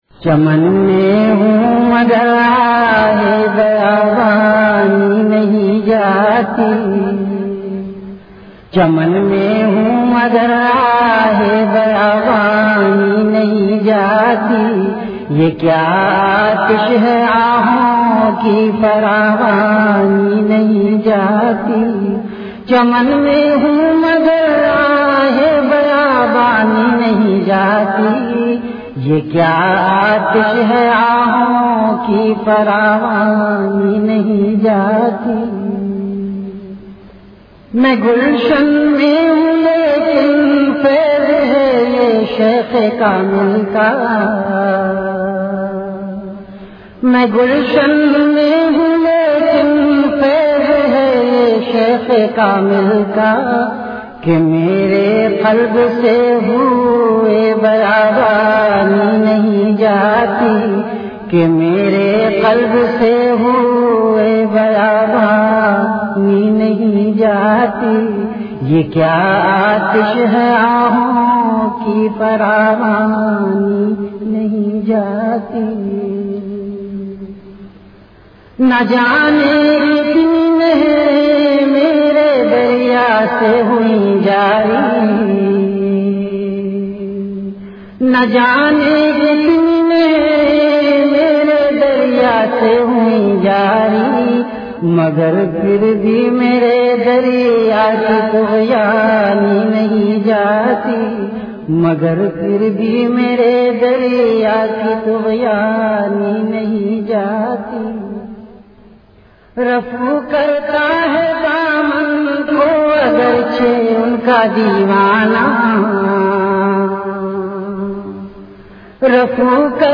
CategoryAshaar
VenueKhanqah Imdadia Ashrafia
Event / TimeAfter Magrib Prayer